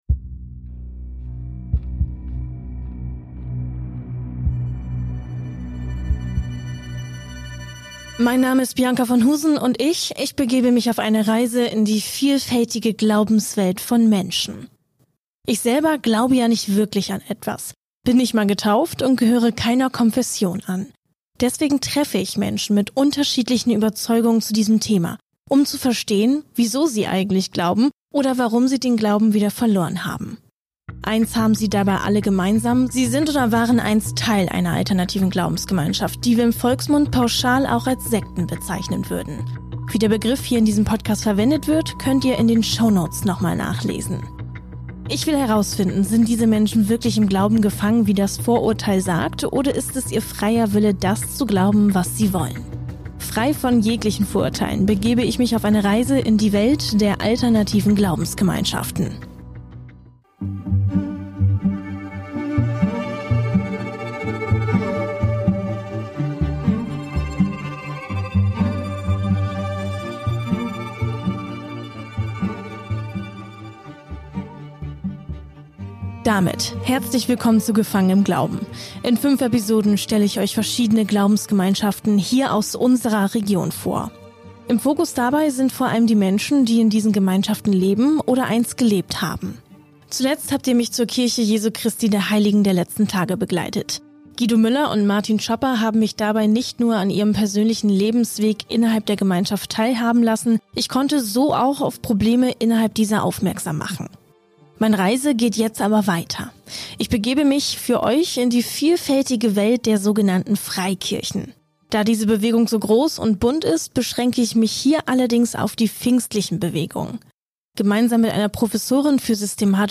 Gemeinsam mit einer Professorin für systematische Theologie und Ökumene versucht sie das Phänomen der Pfingstgemeinden zu verstehen und einzuordnen. Ein ehemaliges Mitglied einer solchen Kirche, bringt außerdem Licht ins Dunkle und erzählt, wie es war in einer freien evangelischen Gemeinde aufzuwachsen.